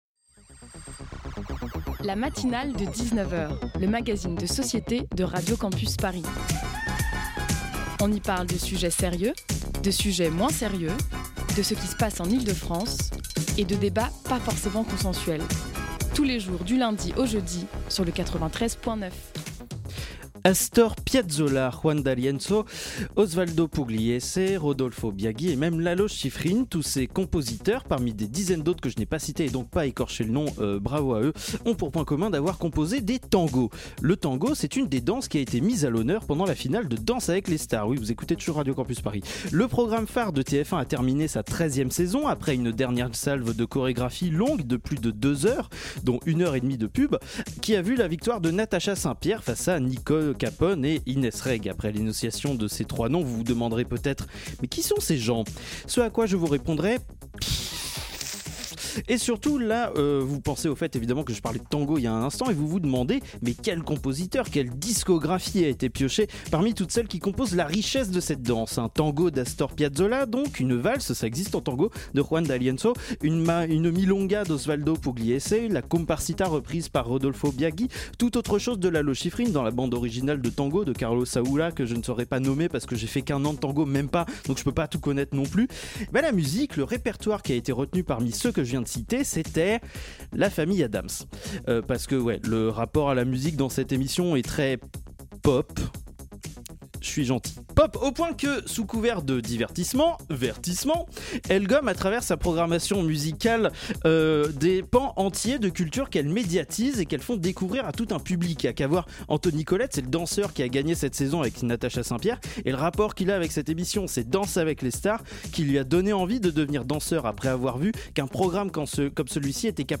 Tous les soirs, des reportages pas chiants, des chroniques épiques et des interviews garanties sans conservateur viendront ponctuer cette heure où l’ennui subit le même sort que Bonaparte : le bannissement.